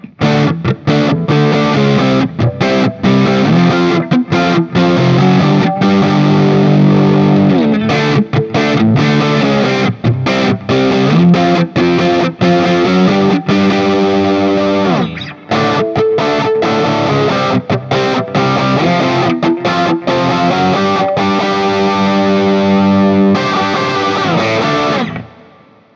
JCM-800
A Barre Chords
As usual, for these recordings I used my normal Axe-FX III  setup through the QSC K12 speaker recorded direct into my Mac Pro using Audacity.
For each recording I cycle through the neck pickup, both pickups, and finally the bridge pickup.
Guild-Nightbird-DX-JCM800-A.wav